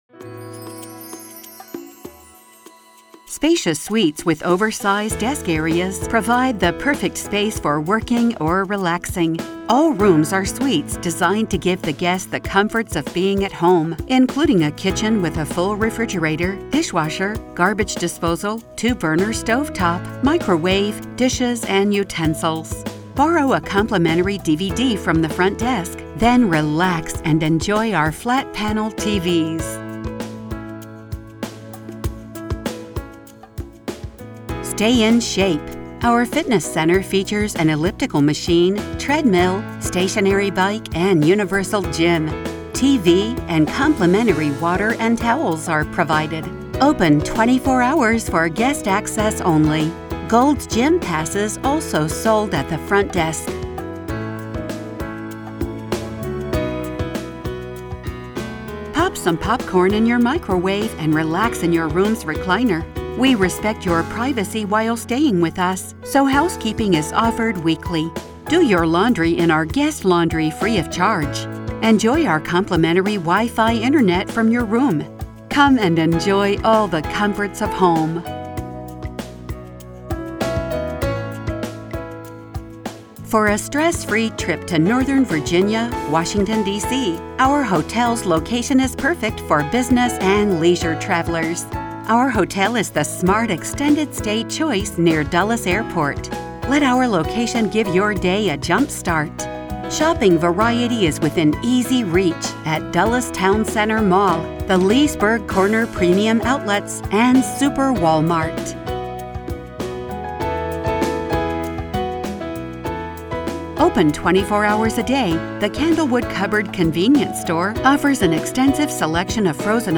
Voice Over Examples
We make on-hold messages for all businesses. Here are a few of our example recordings.